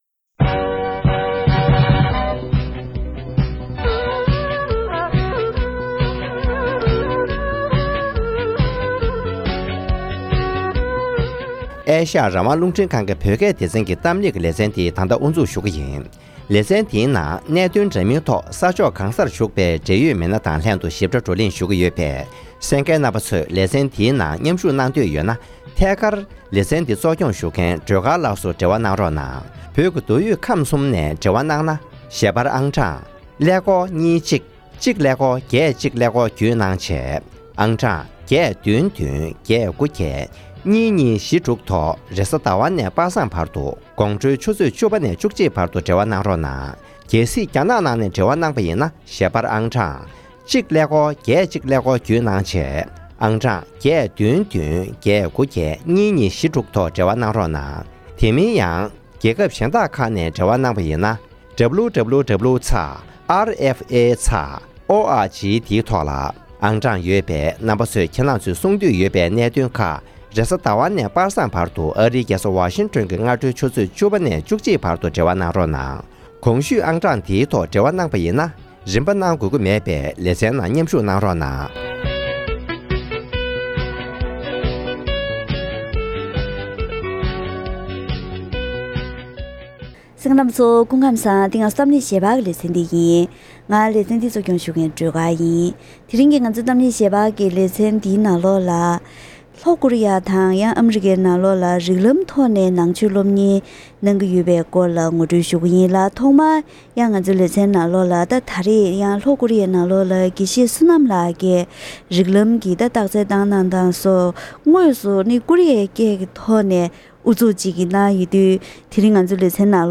གཏམ་གླེང་